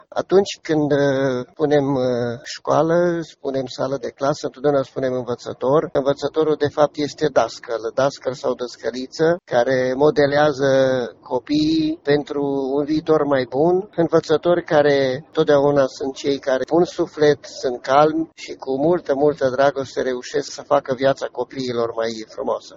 Inspectorul școlar general al județului Mureș, Ioan Macarie, i-a felicitat pe învățători și a subliniat importanța pe care aceștia o au în formarea copiiilor: